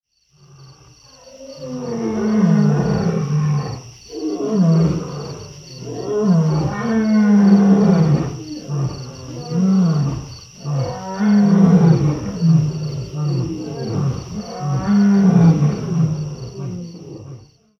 Support the Big Cats with this Ambient CD!